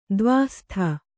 ♪ dvāhstha